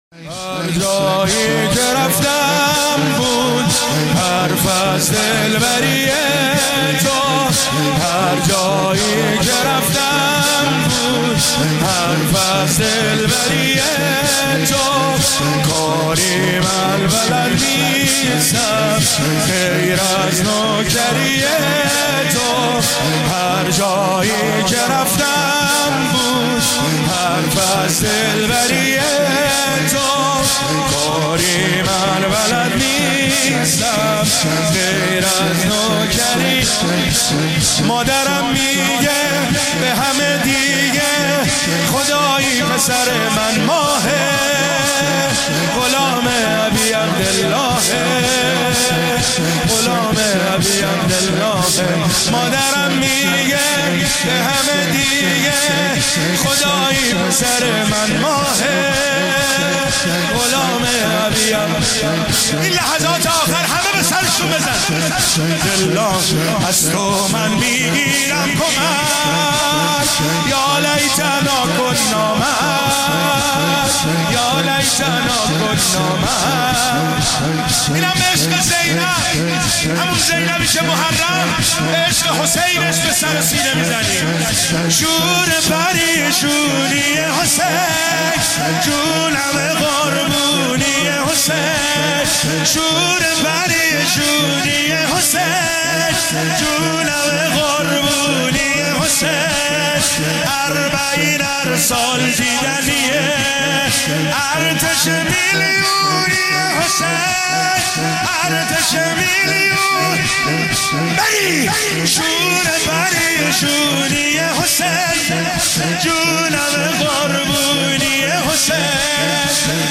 پانزدهمین اجتماع مدافعان حرم در مهدیه تهران